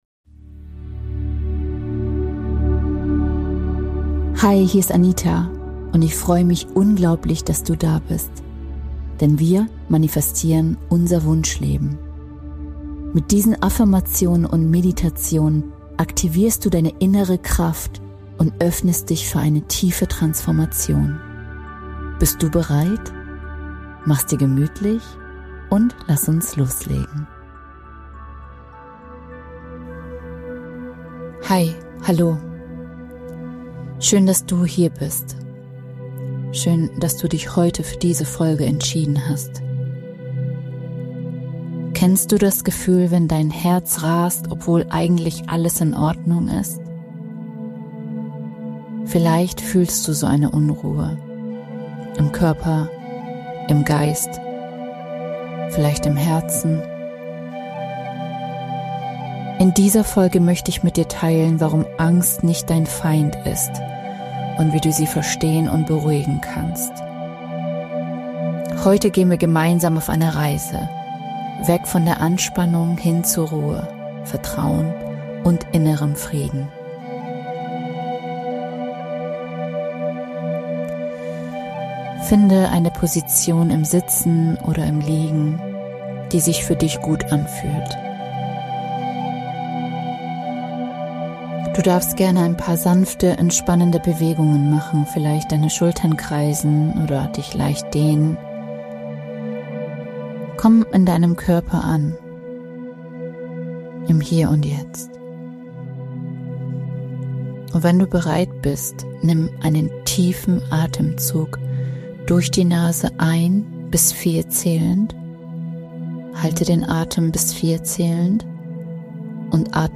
In dieser Meditation lade ich dich ein, einen Moment der Geborgenheit und inneren Ruhe zu genießen.